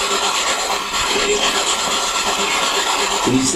Spirit Box Clip 6
About the clip: Two different spirits have an exchange in this clip recorded during an SB11 spirit box session in the basement. Listen closely, and you'll hear one spirit speak followed right away by a child replying. The very last loud voice is me as I begin to ask a question, and the child speaks right up until I start to speak, so be sure to listen to the child's complete response!
CD Audio Quality I hear a female ask, "Who is that?"